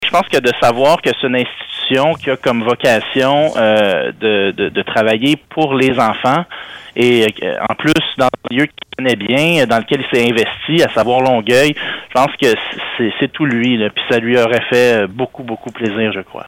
En entrevue sur nos ondes